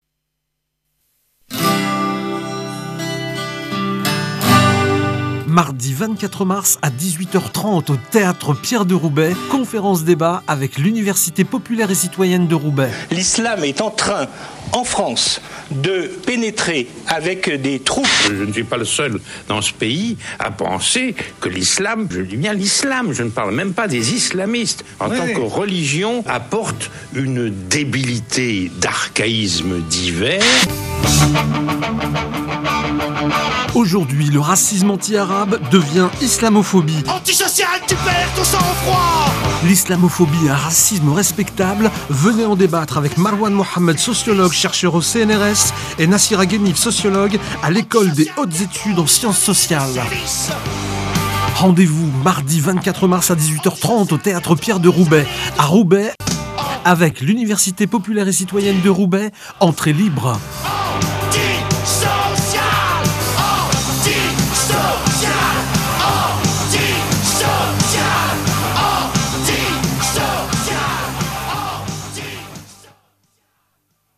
BANDE ANNONCE